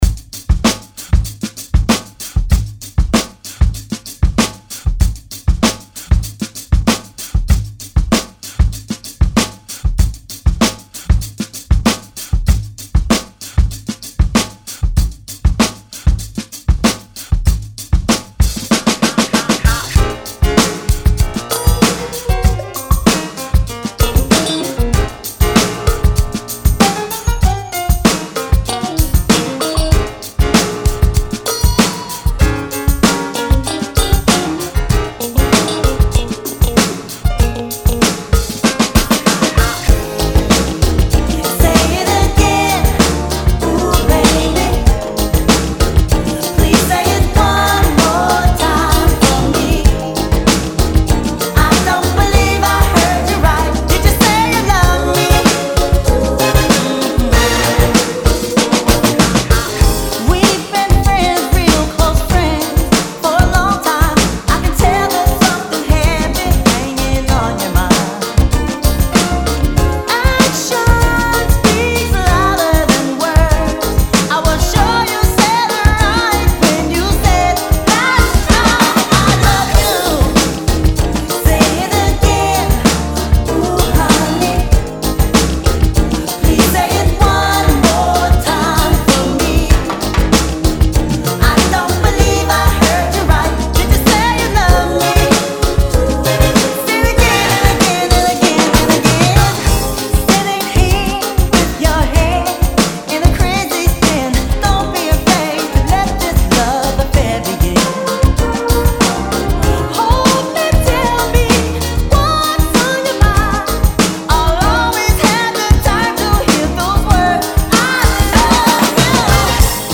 96 Bpm Genre: 80's Version: Clean BPM: 96 Time